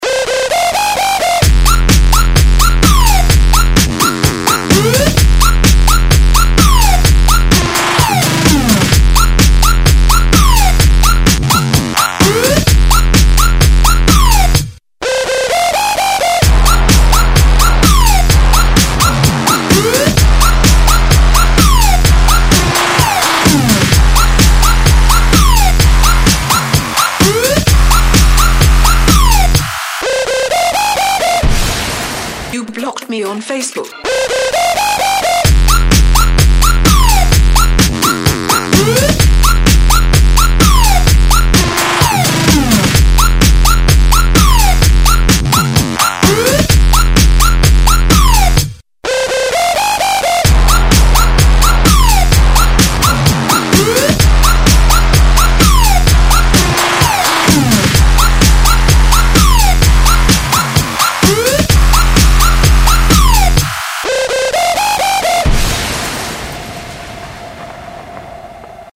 • Качество: 128, Stereo
Классный клубный рингтон